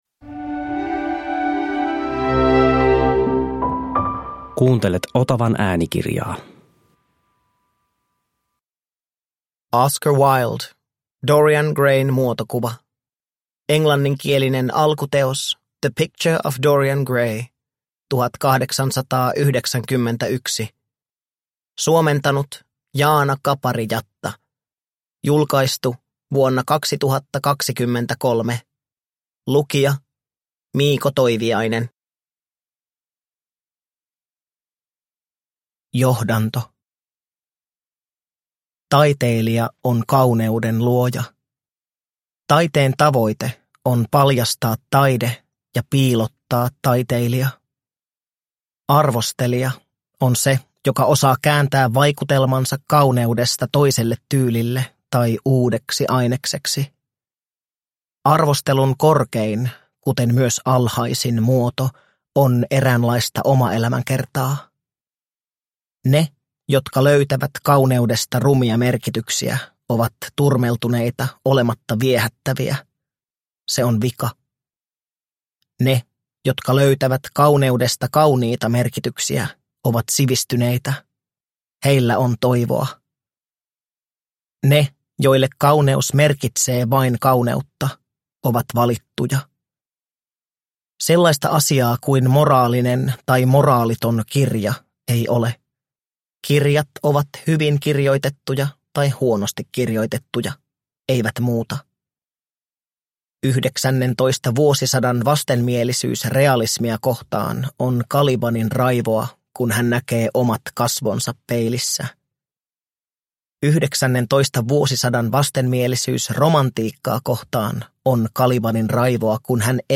Dorian Grayn muotokuva – Ljudbok